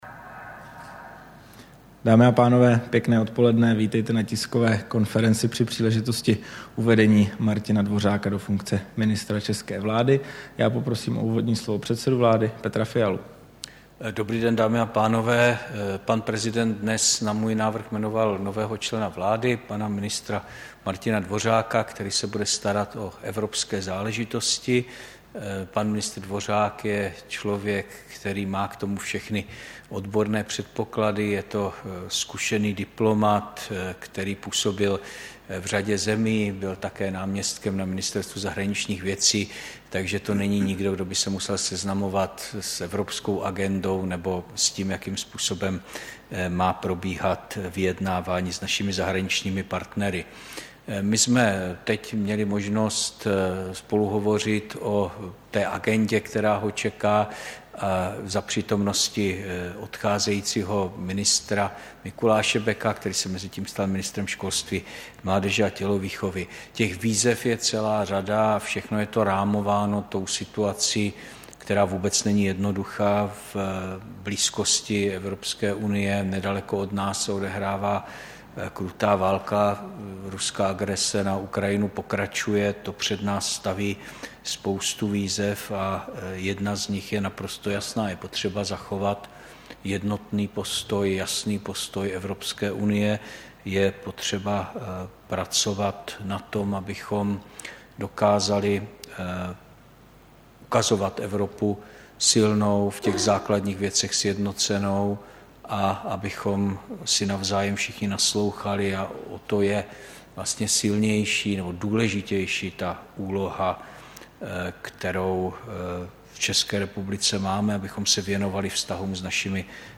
Tisková konference po uvedení ministra pro evropské záležitosti Martina Dvořáka do funkce, 4. května 2023